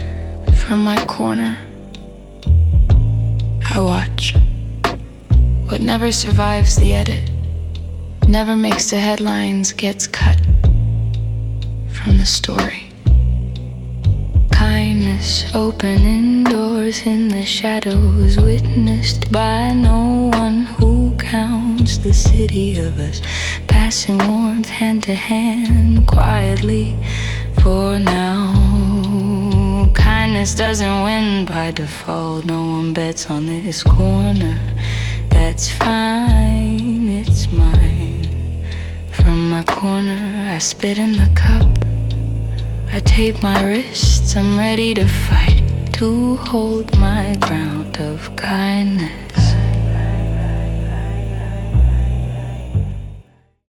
The soundscape and visuals were created with AI.